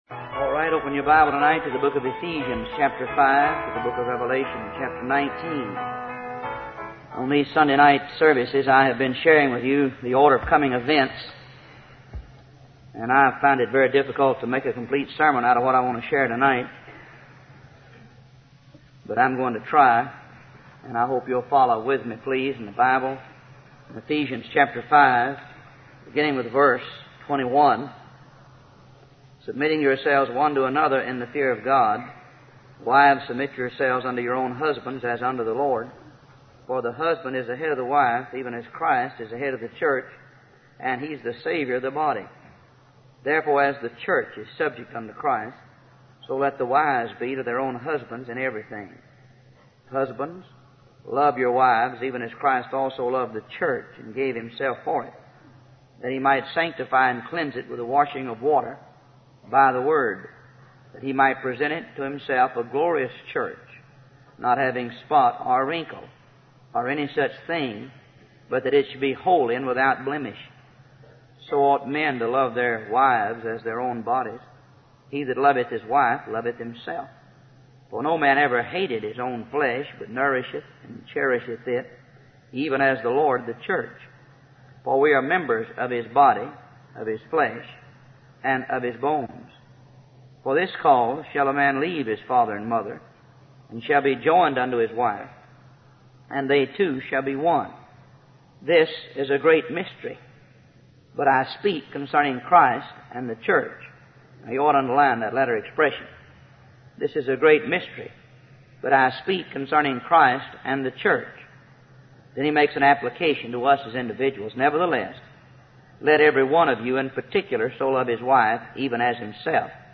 Professing themselves to be wise, they became fools. Wise Desire Ministries helps convey various Christian videos and audio sermons.